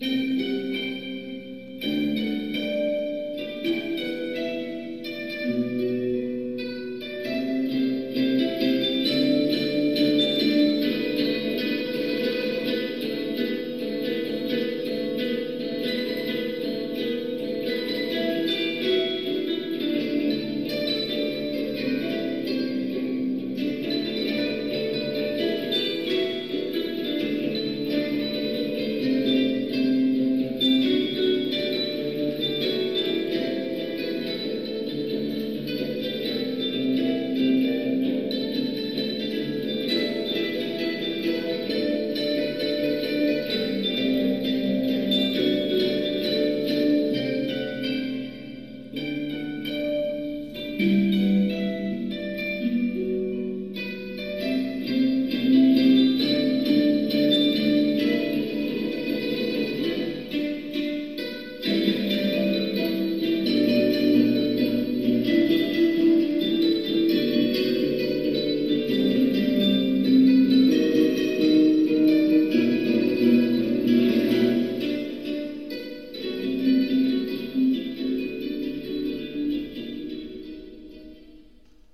箏1
箏2
十七絃